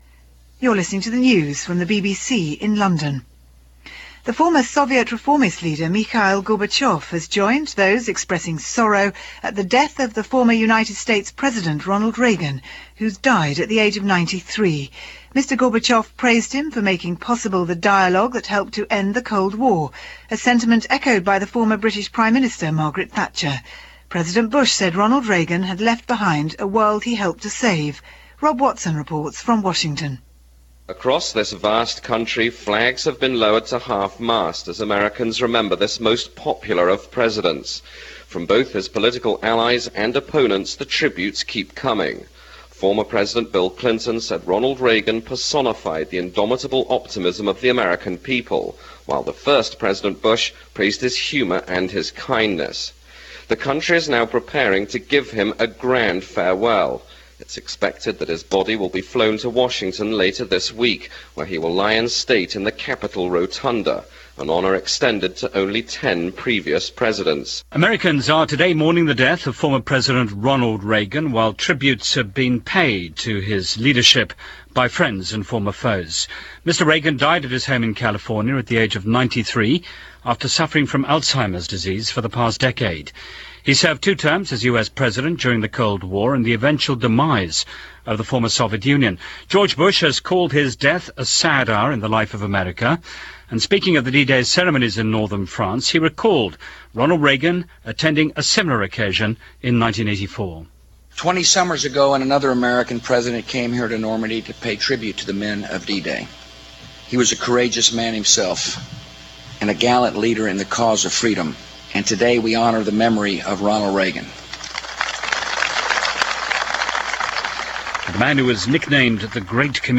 June 5, 2004 - Ronald Reagan: Death Of A President - The World Chimes In - BBC World Service reaction to the death of the former President.